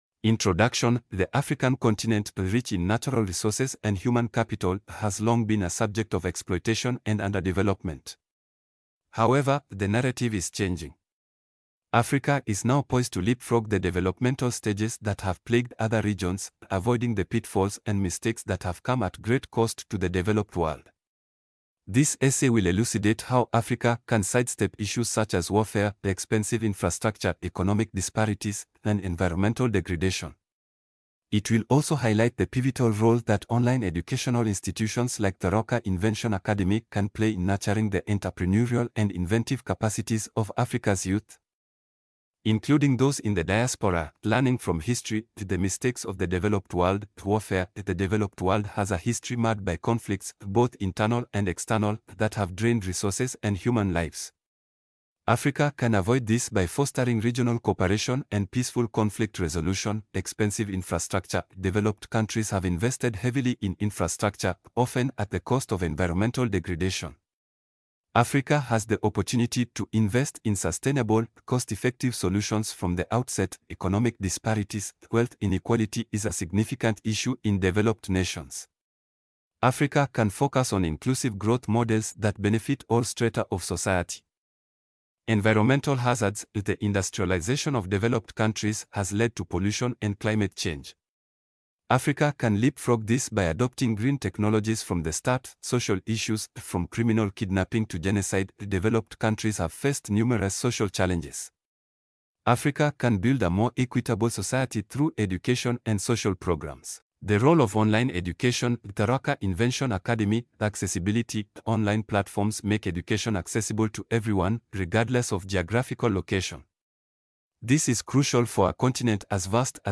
Narration.mp3